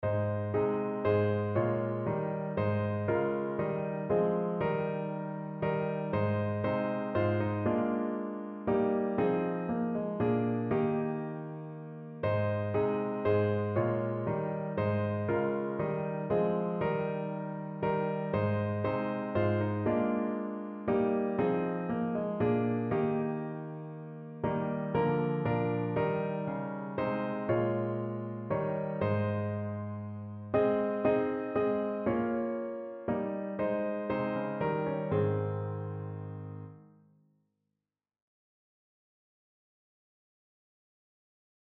Notensatz 2 (4 Stimmen gemischt)